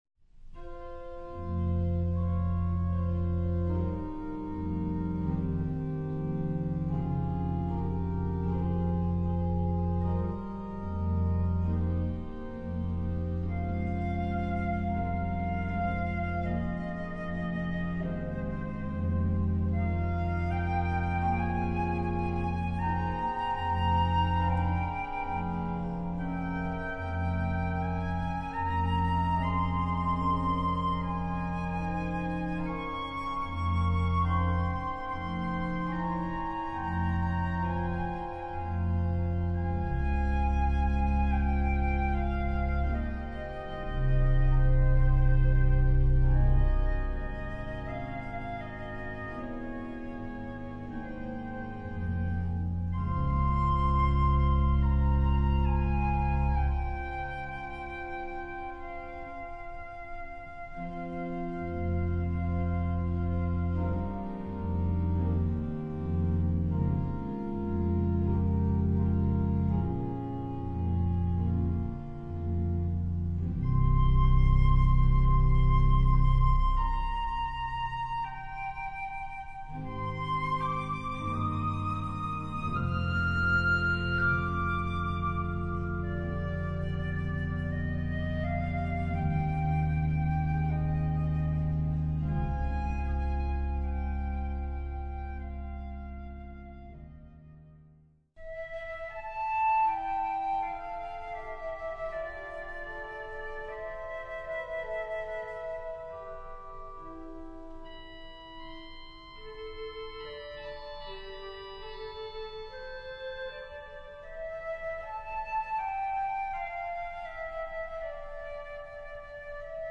Voicing: Instrument and Organ